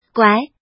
怎么读
guái
guai2.mp3